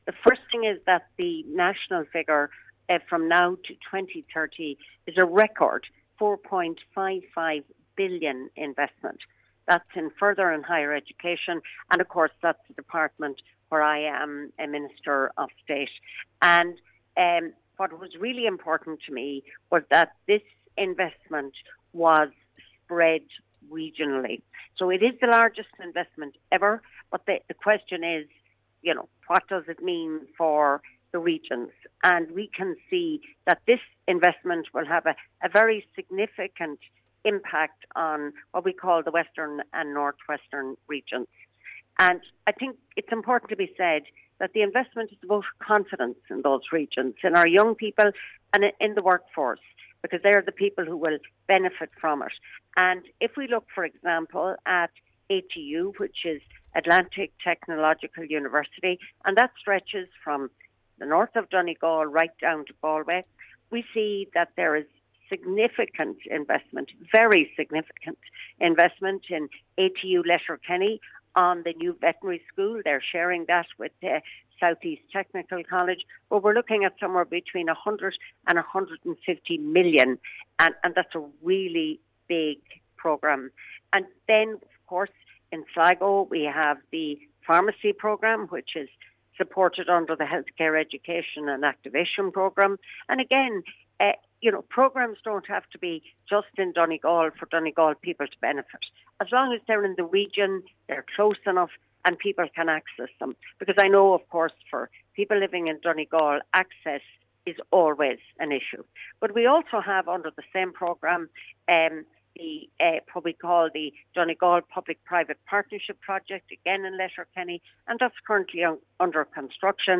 Minister of State for Higher Education, Marian Harkin says that the county will also benefit from projects in neighbouring counties: